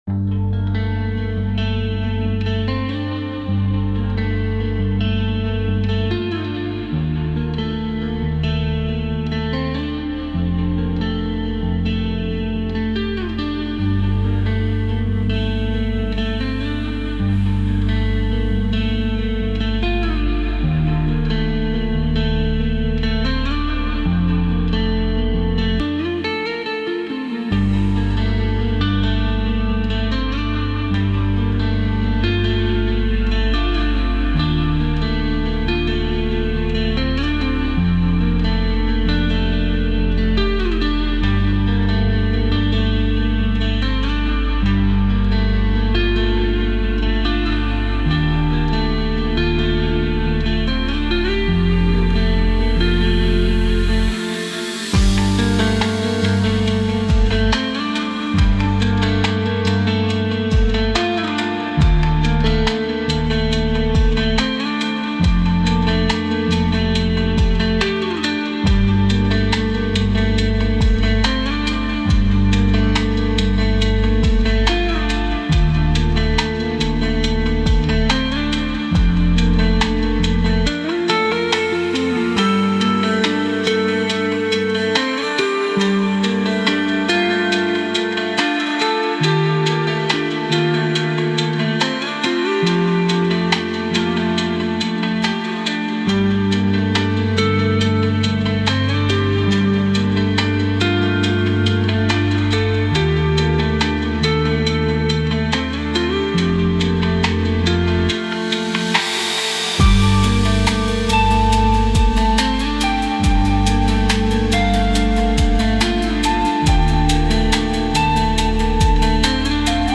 موسیقی بی کلام غم‌انگیز